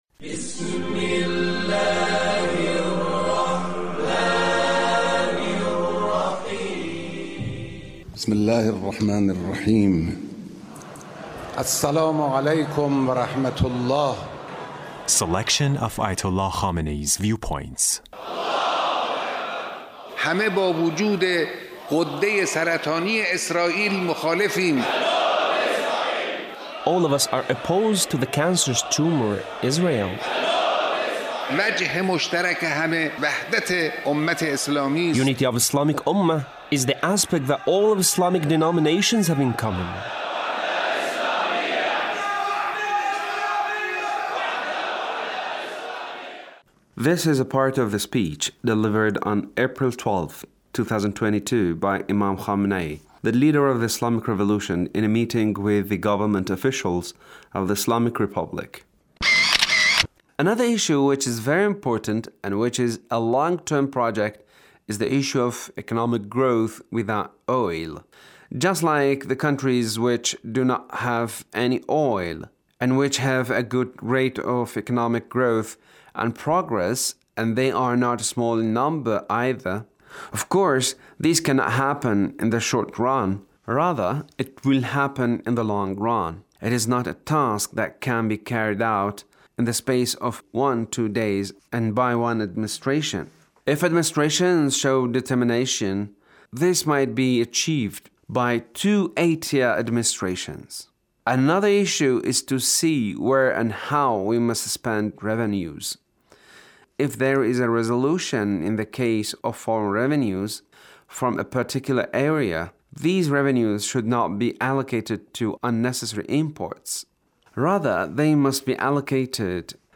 The Leader's speech in a meeting with Government Officials